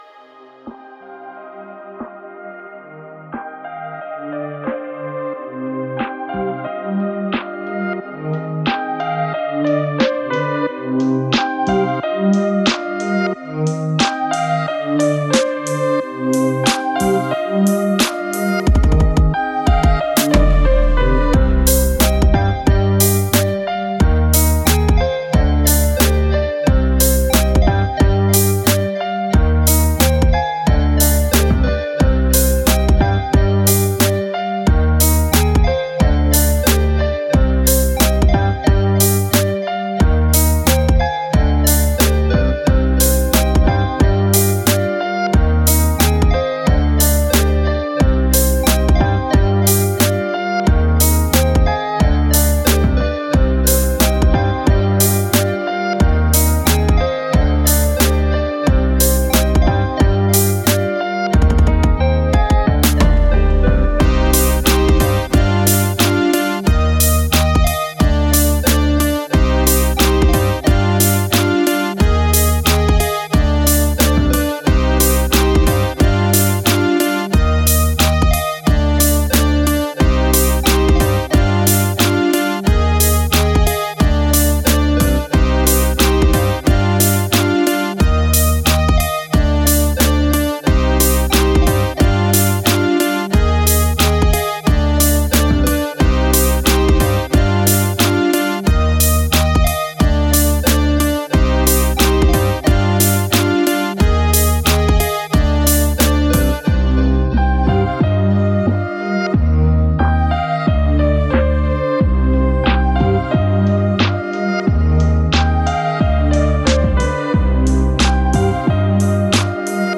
Musique Rap, trap, boombap libre de droit pour vos projets.